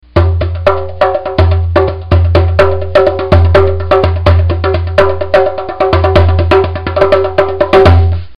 Bongo Drum